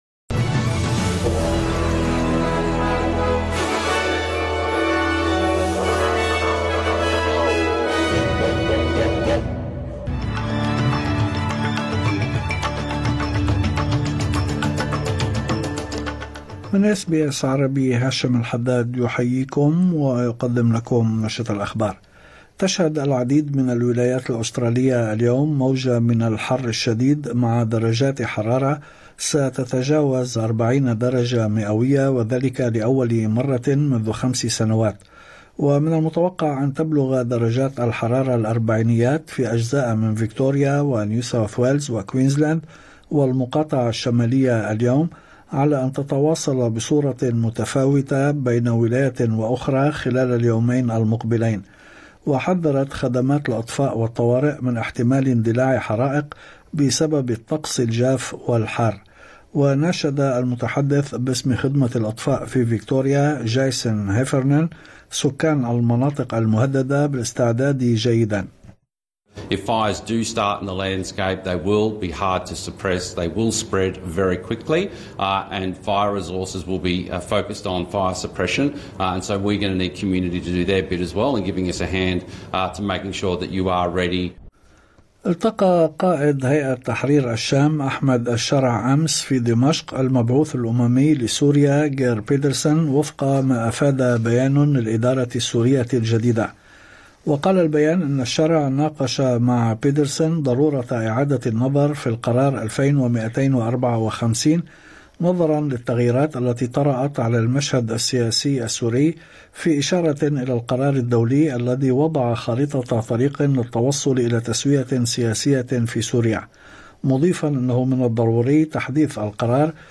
نشرة أخبار الظهيرة 16/12/2024